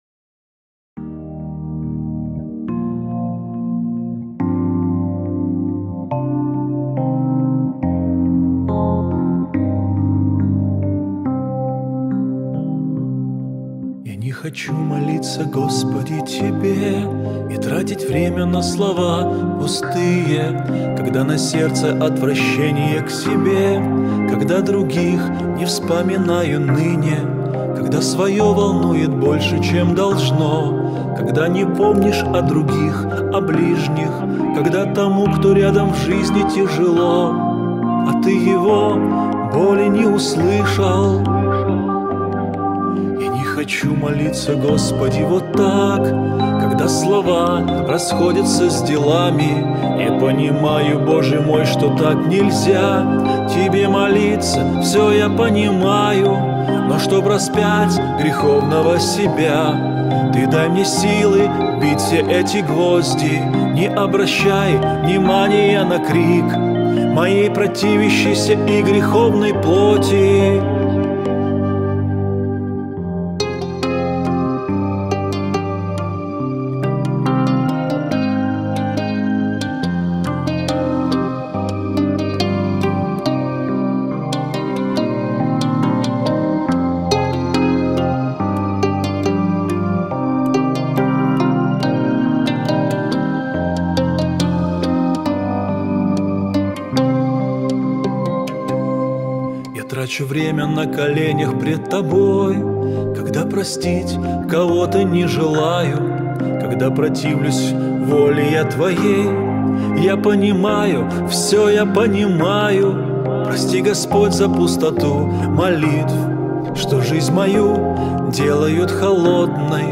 песня
109 просмотров 127 прослушиваний 9 скачиваний BPM: 75